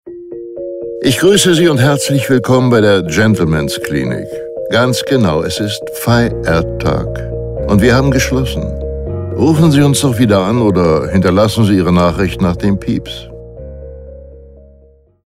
Telefonansage Klinik – Krankenhaus – Spital
Ansage 3 – Feiertag mit der deutschen Bruce Willis Stimme